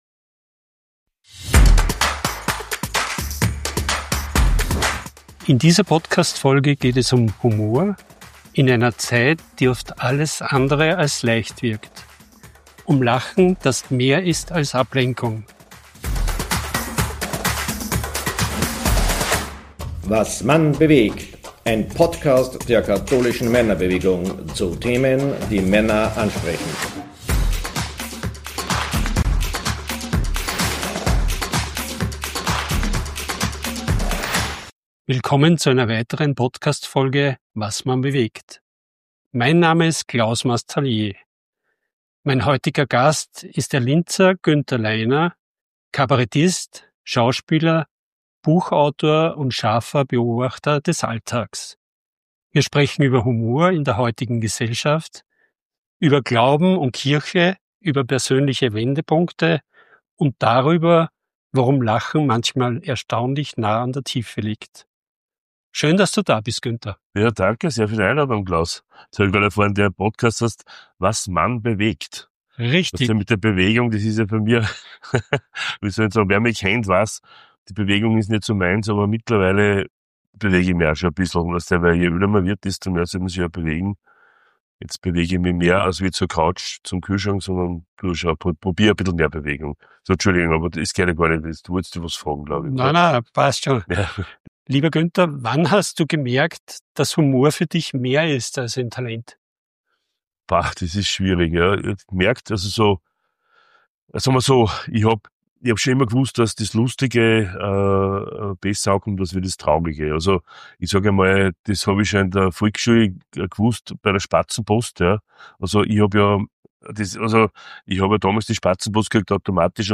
im Gespräch mit Kabarettist